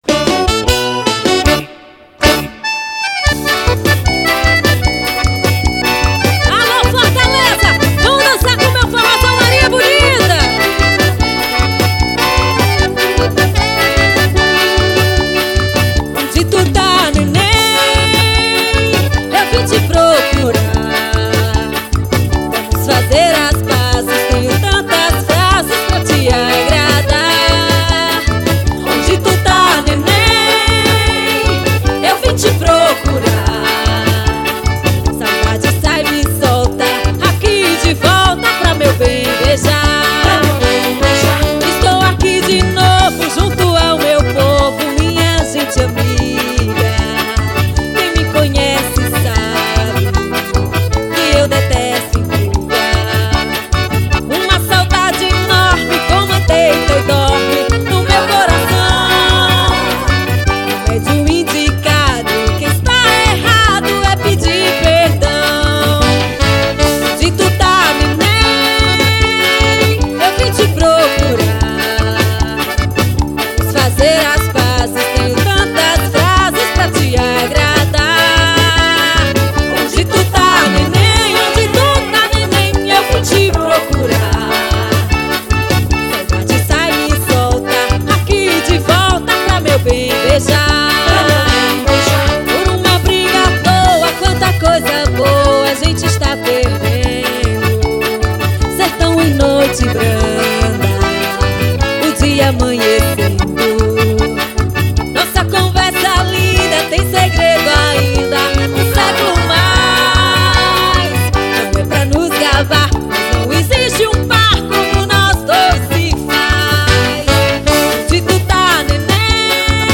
Ao vivo em Fortaleza.